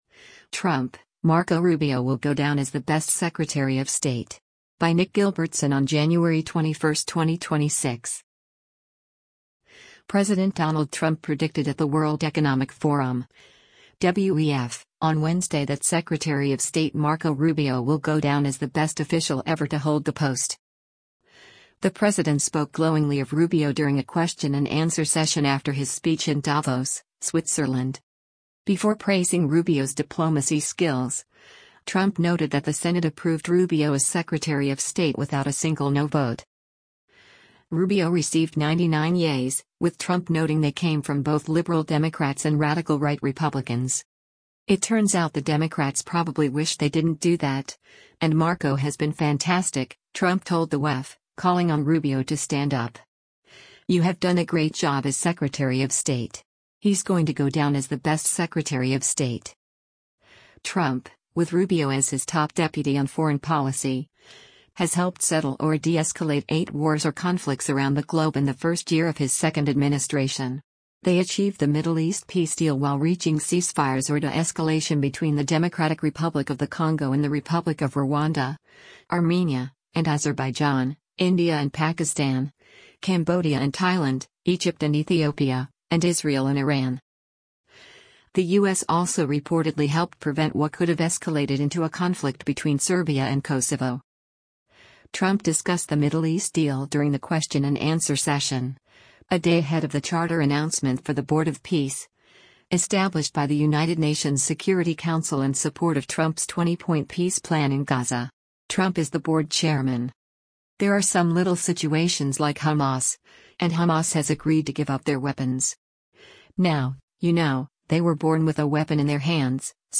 The president spoke glowingly of Rubio during a question-and-answer session after his speech in Davos, Switzerland.